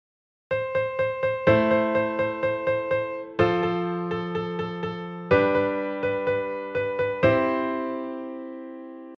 コードAm・F・Gの部分を、それぞれの3度の音（C・A・B）に移動させてみます。
melodyrythm2.mp3